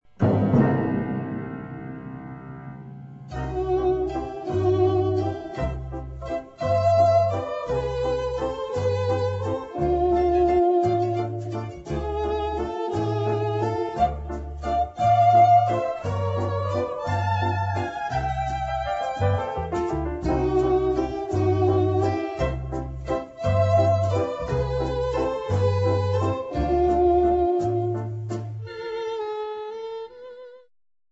harmonic medium instr.